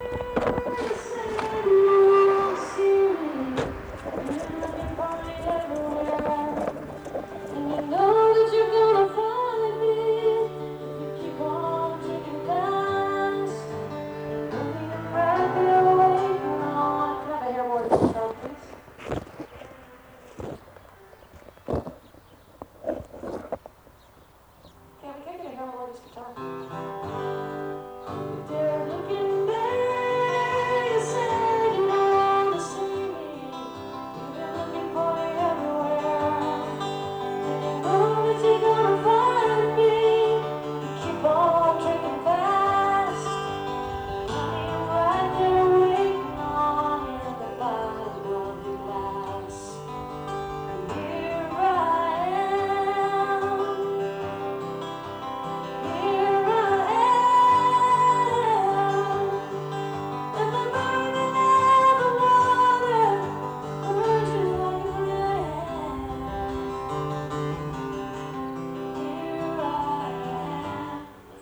(soundcheck)